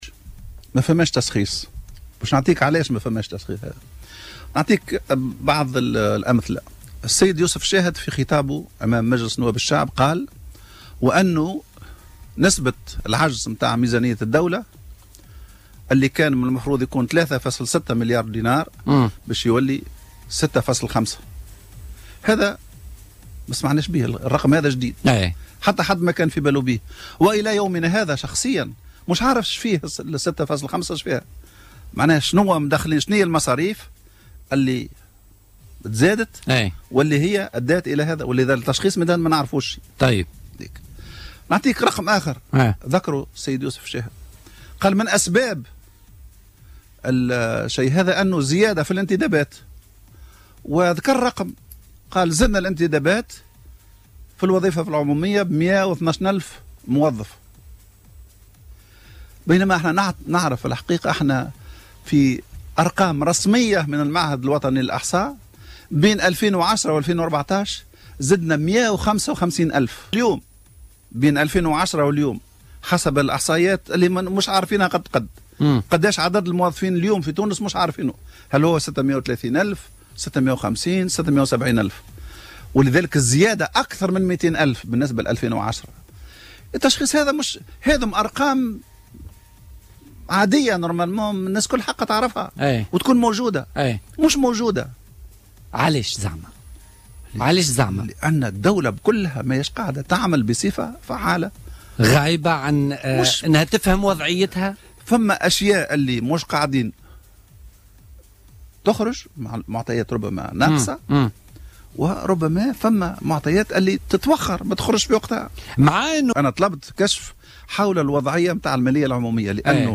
وأكد في حوار ببرنامج "الحدث" على "الجوهرة أف أم" ضرورة تشكيل هذه اللجنة حتى يمكن التعرف على الوضع الحقيقي للمالية العمومية في تعليقه على تضاعف قيمة عجز ميزانية الدولة المتوقع من 3.6 مليارات دينار إلى 6.5 مليارات دينار.